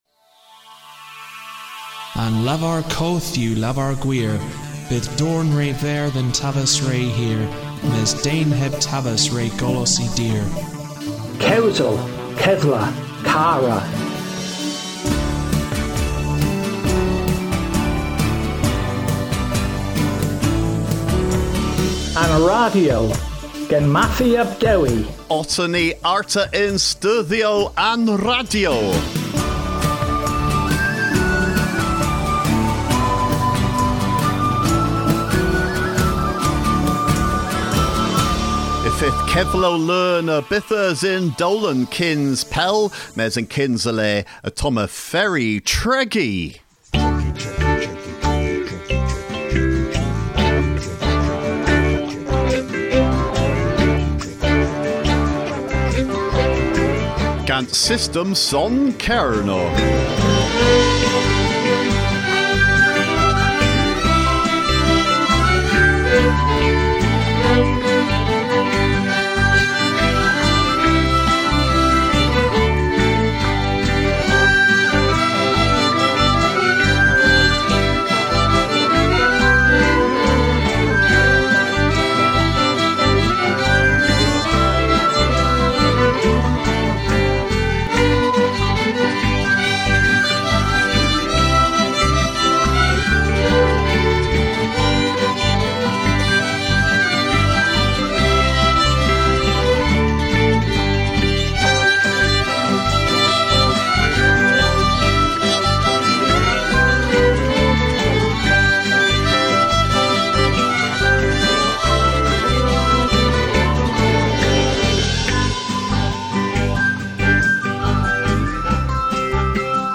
Weekly Cornish-language podcast
We have the Cornish news, Celtic world news, and a short Cornish lesson based around the Cornish word for ‘France’. We have music in Cornish from Cornwall, as well as from Ireland, Man, Brittany and Wales.